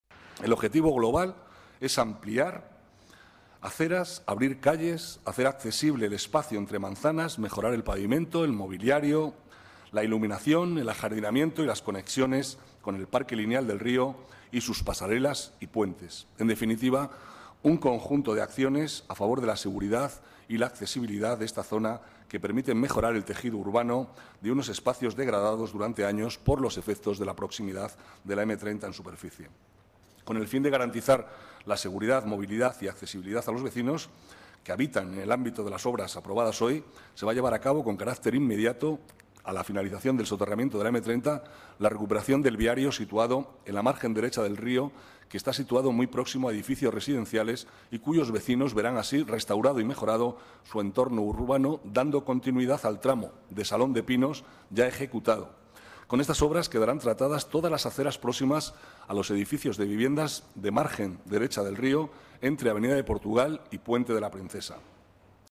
Nueva ventana:El vicealcalde, Manuel Cobo, explica cuál es el objetivo global de la recuperación del entorno del río Manzanares